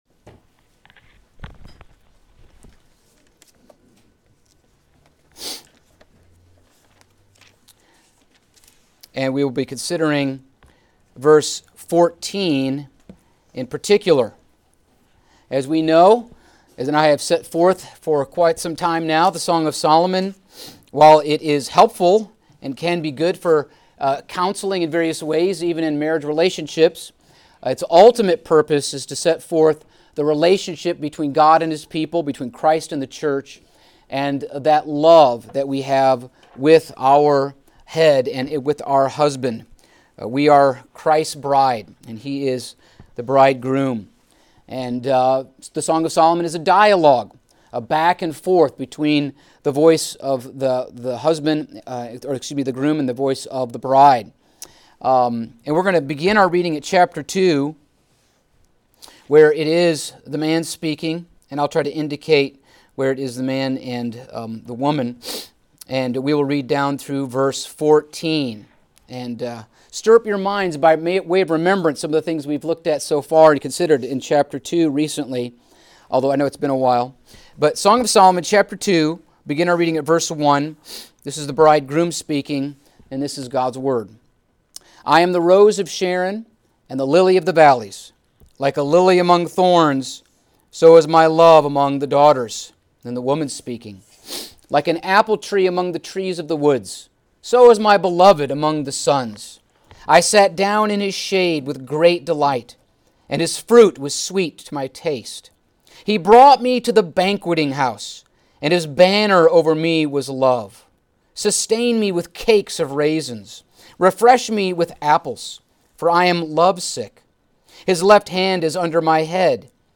Passage: Song of Solomon 2:14 Service Type: Sunday Afternoon